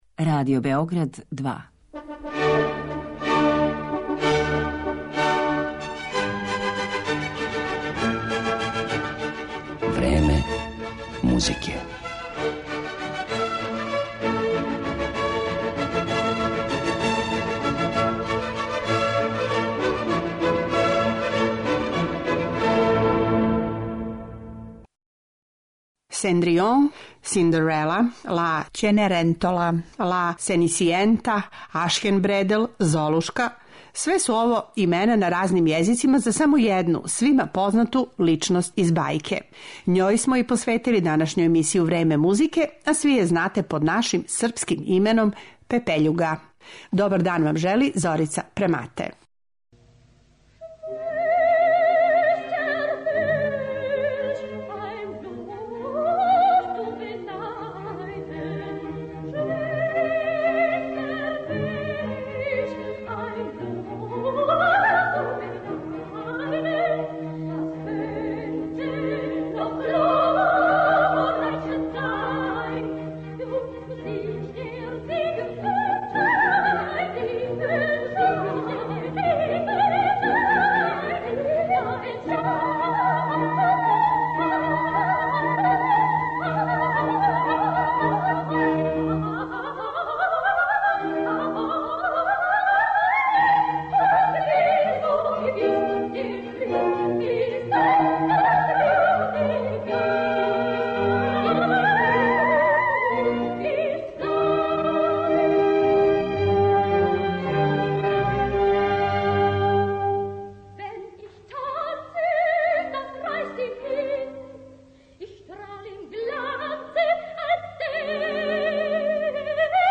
Њој смо и посветили данашњу емисију Време музике, а слушаћете музику из опера и балета о овој јунакињи бајке Шарла Пероа, из пера Никола Изуара, Ђакома Росинија, Жила Маснеа, Паулине Вијардо, Јохана Штрауса Сина и Сергеја Прокофјева.